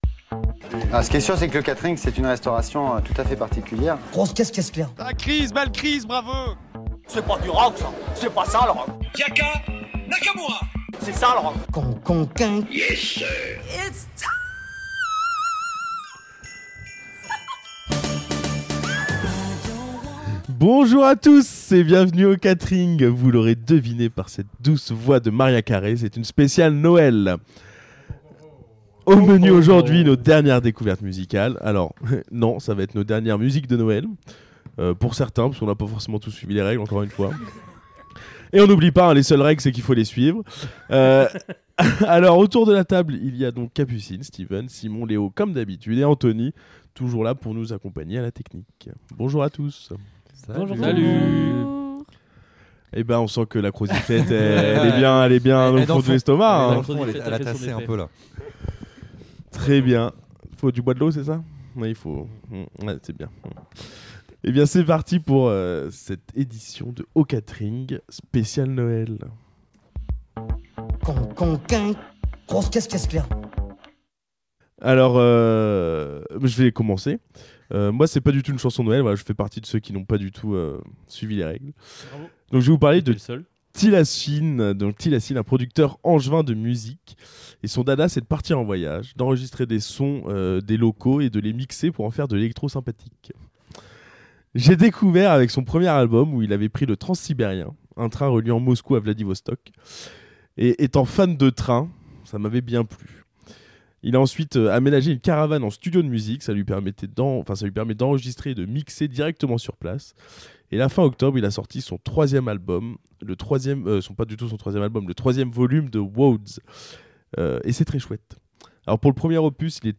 Au menu de ce mois d’octobre, une émission un peu spéciale, malgré le changement d’heure, impossible pour l’équipe de se retrouver autour d’une même table !! Pas de grande tablée, pas de bruit de fond de bar bondé, pas de débats endiablés, pas de public plus intéressé par leur consommation que nos élucubrations, mais avec l’appui des réseaux sociaux.. des vocaux !!